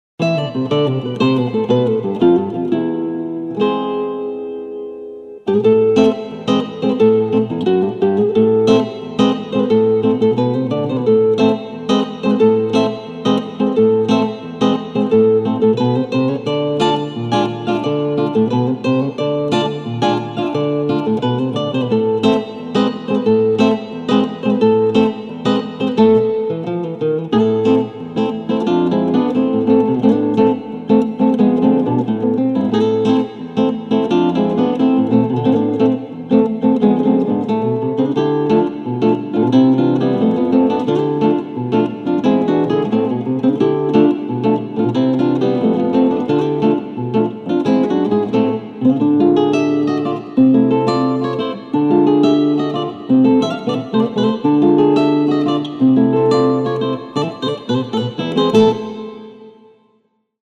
- Pieces for guitar duo -